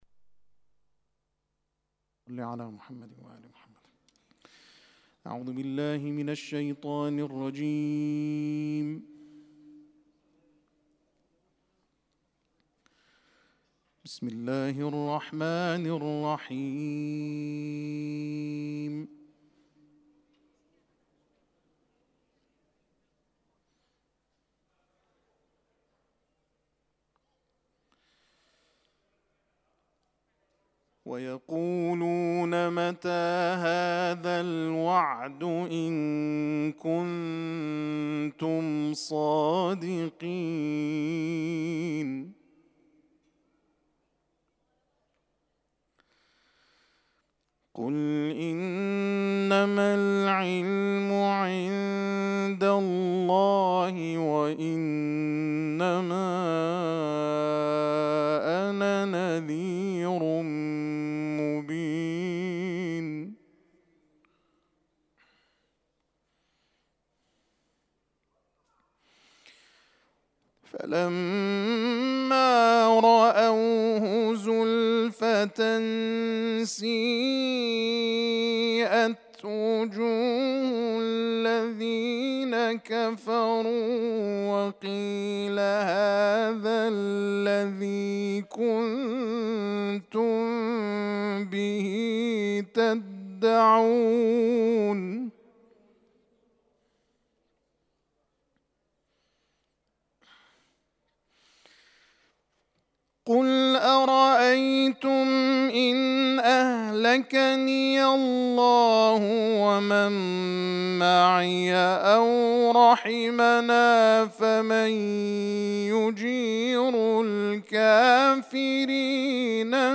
جلسات درس اخلاق